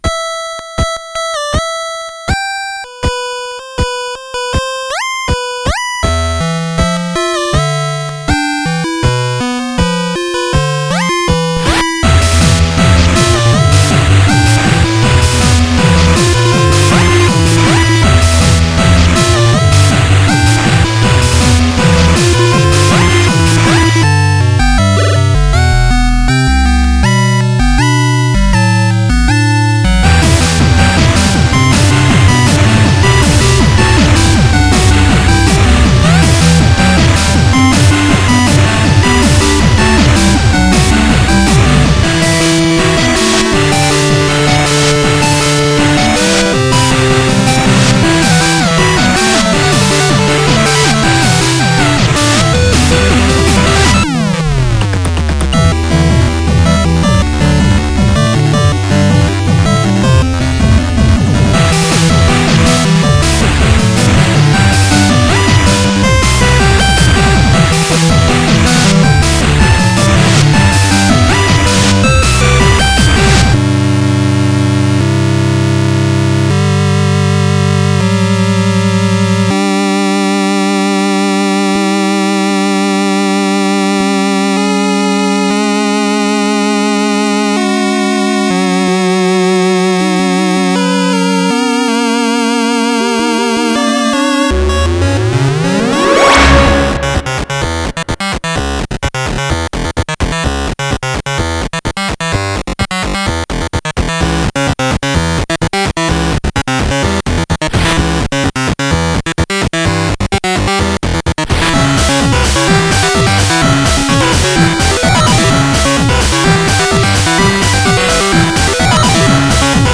2x LSDj/ 2x DMG.